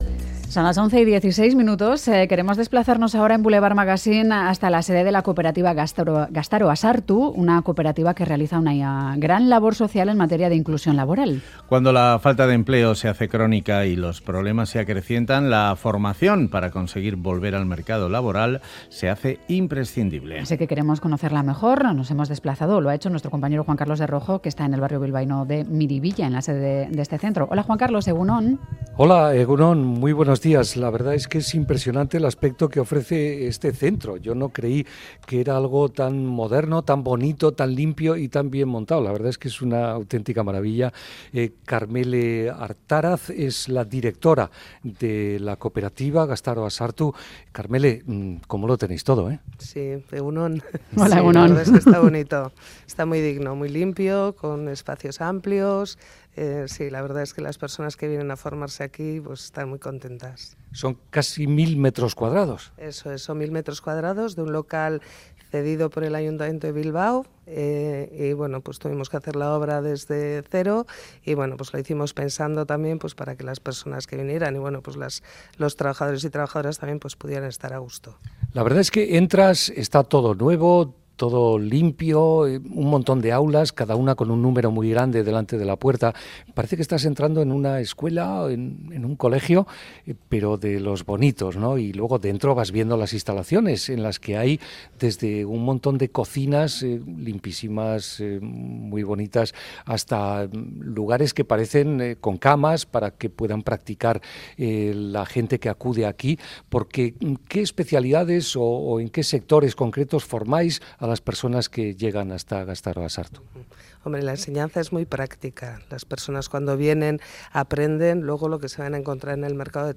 Visitamos el centro del barrio de Miribilla en Bilbao donde se atiende cada año a 6.000 personas